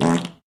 epic_bellow_03.ogg